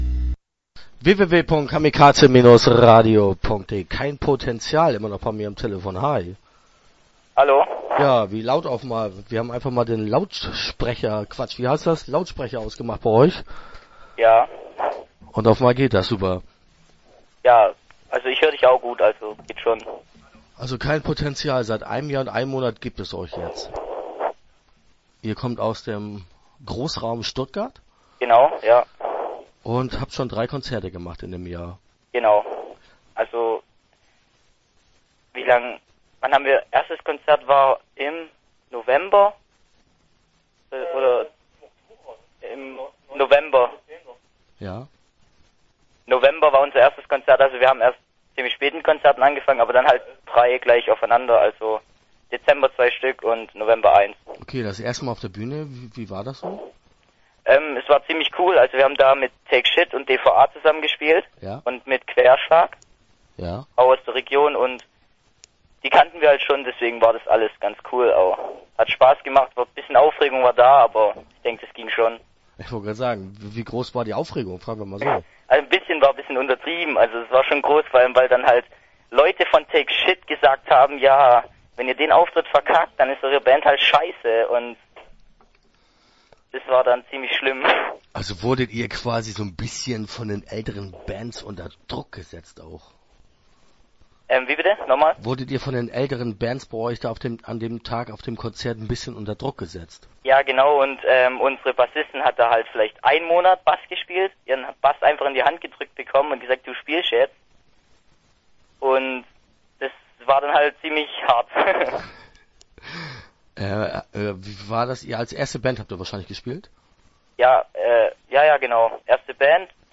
Kein Potential - Interview Teil 1 (6:10)